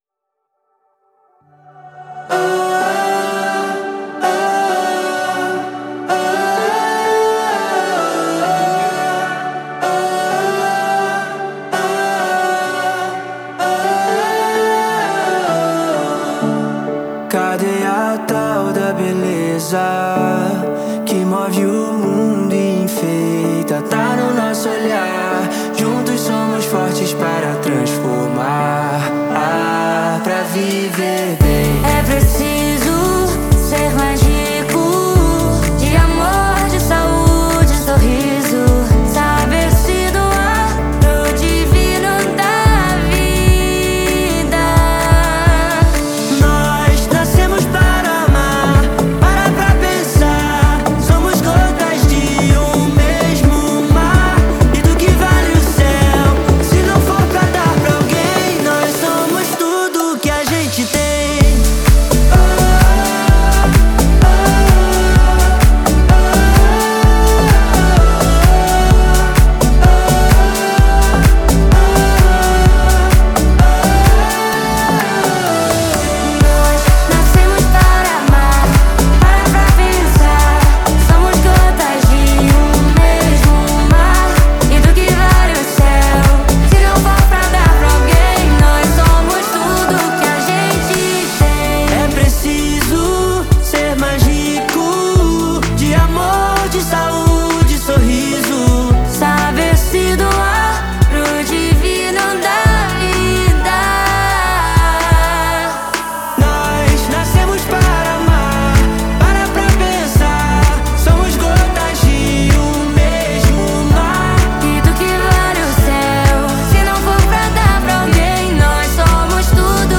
это энергичная и мелодичная композиция в жанре EDM